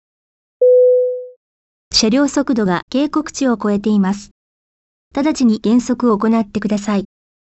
音声案内　改め[音声警告システム]
車両速度警告